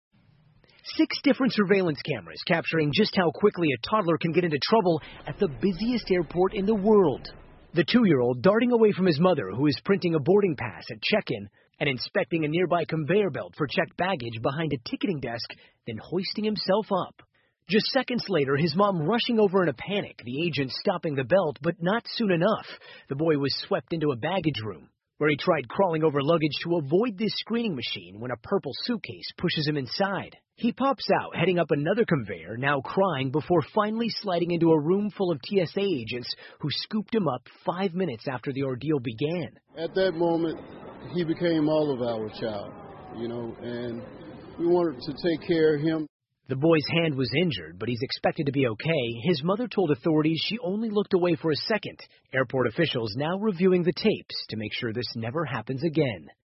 NBC晚间新闻 2岁小孩爬上机场传送带 听力文件下载—在线英语听力室